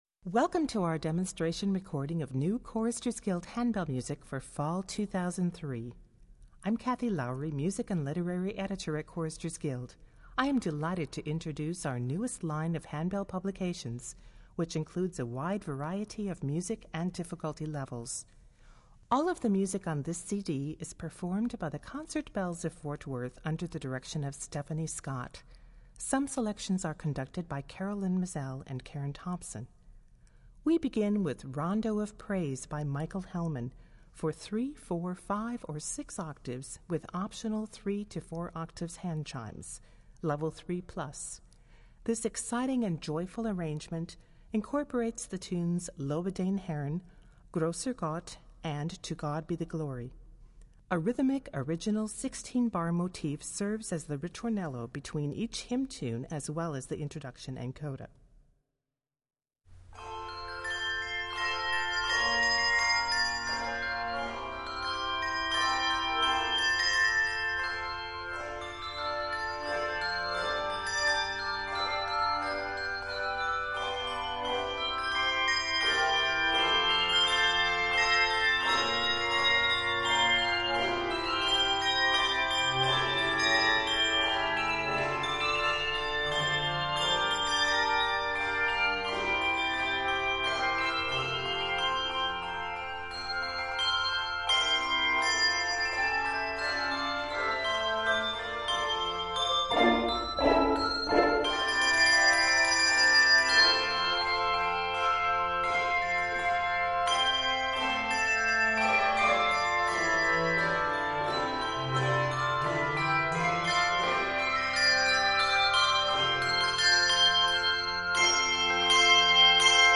Voicing: Handbells 3-6 Octave